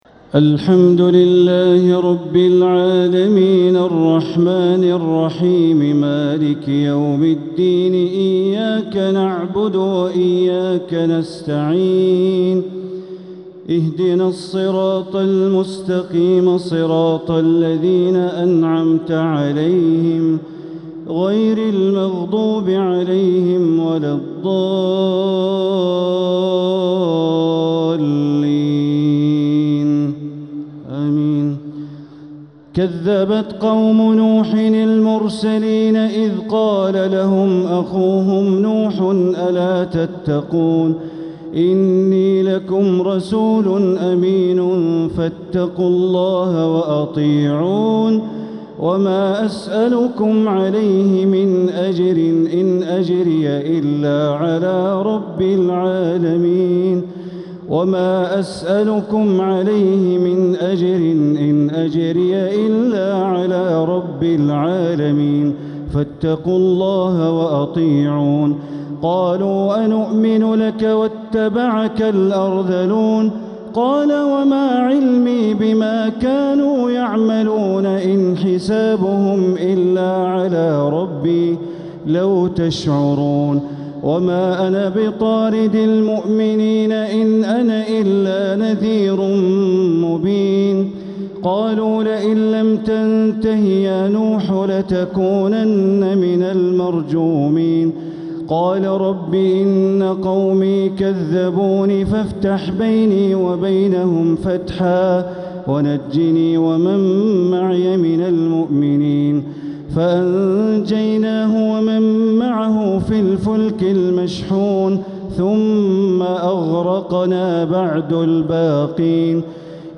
تراويح ليلة 23رمضان 1447هـ من سورتي الشعراء (105) والنمل (1-44) > تراويح 1447هـ > التراويح - تلاوات بندر بليلة